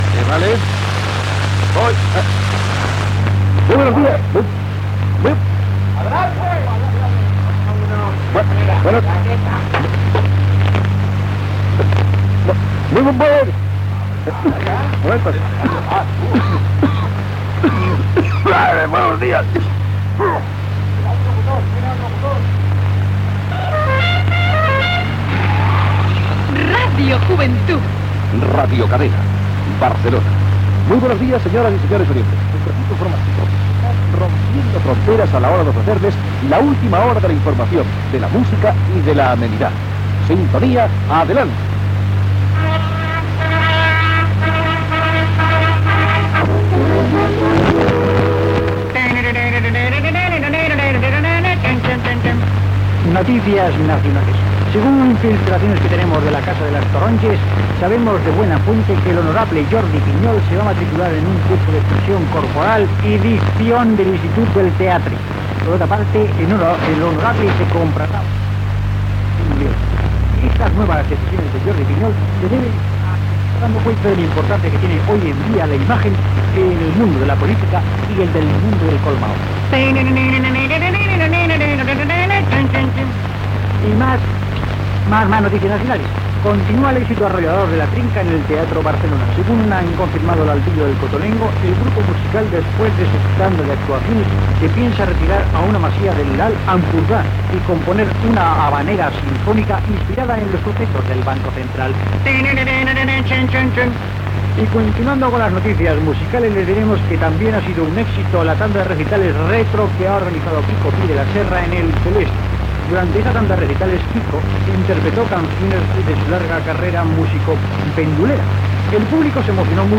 Indicatiu de l'emissora, notícies nacionals, connexió amb El Rocío
Gènere radiofònic Entreteniment